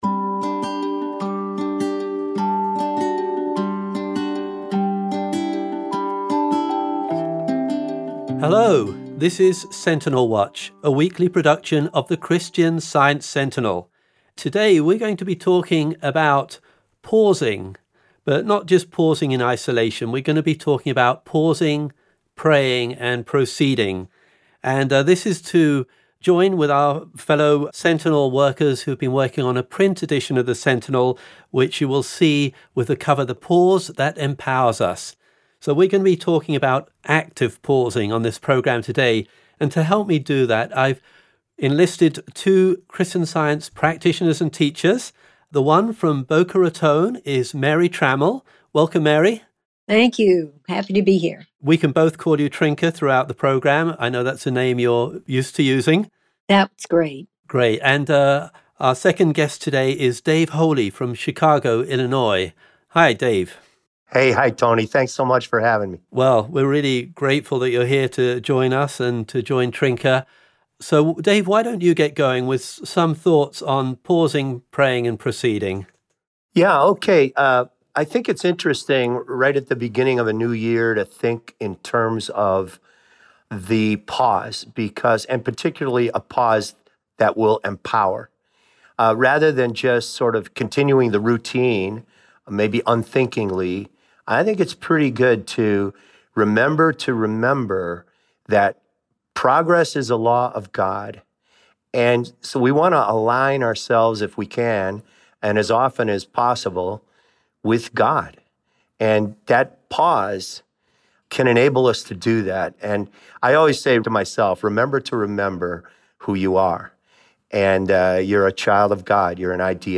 Live audio podcast aired January 5, 2020 on Sentinel Watch